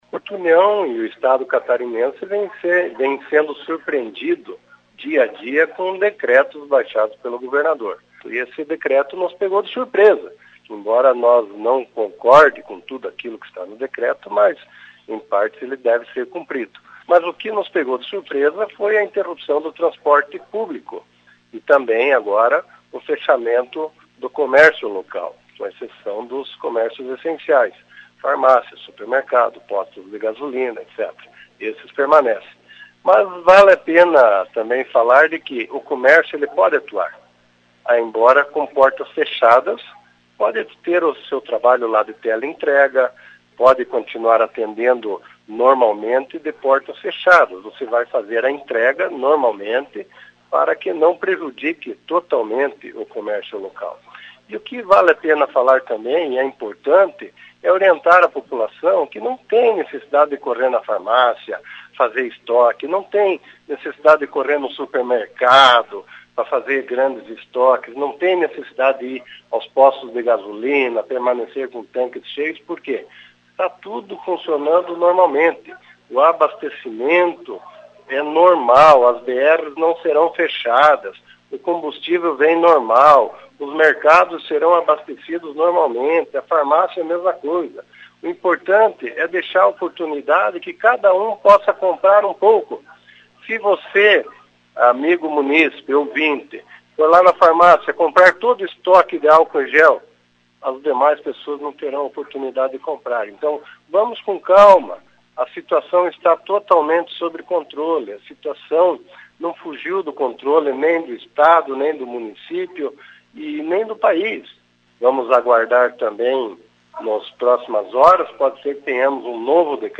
Importante ressaltar que não há casos confirmados no município, entretanto, é necessário a prevenção, como comenta o Prefeito Eliseu Mibach, acompanhe: